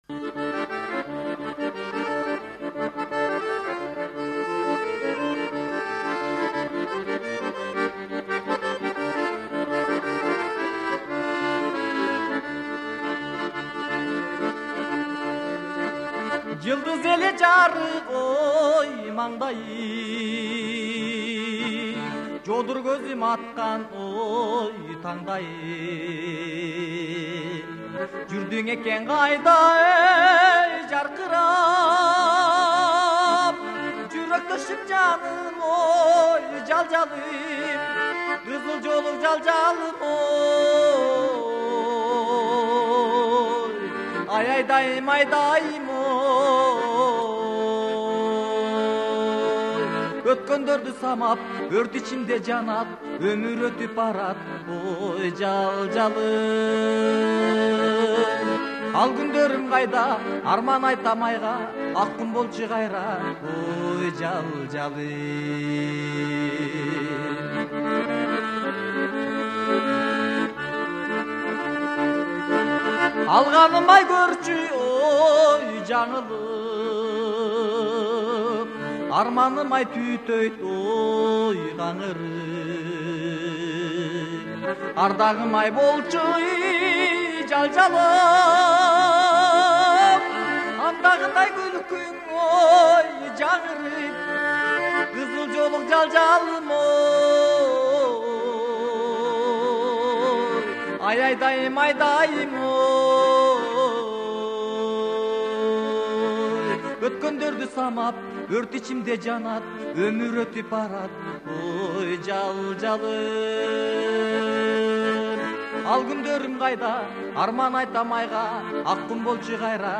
КЫРГЫЗЧА ЖАГЫМДУУ ЖАҢЫ ЫРЛАР